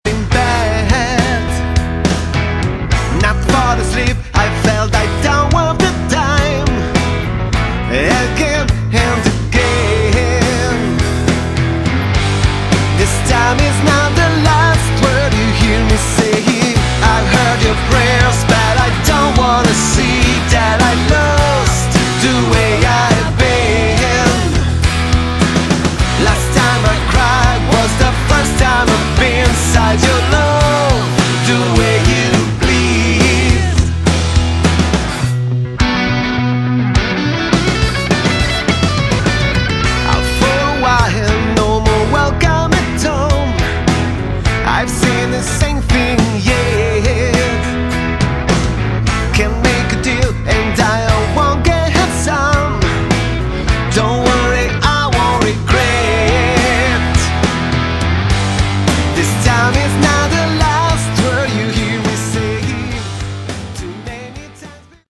Category: Hard Rock
vocals
guitar, piano
bass
drums